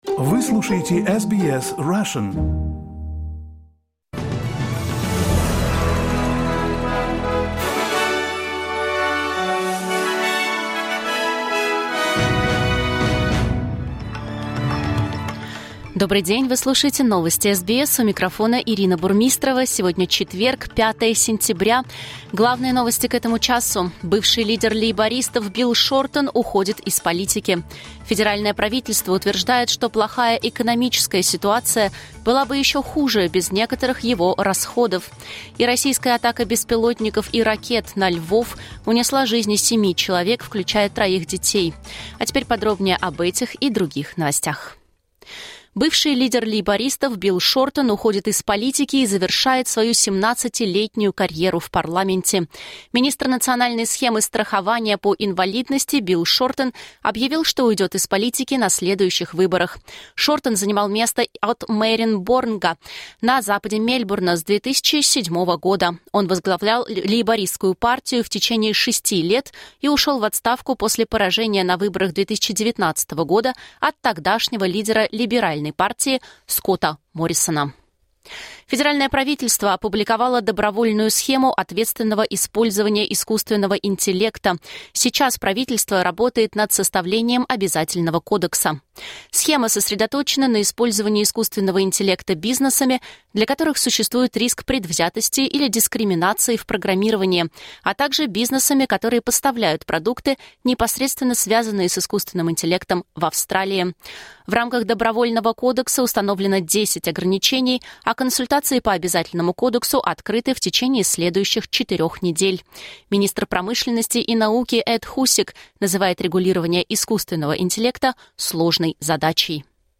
Новости SBS на русском языке — 05.09.2024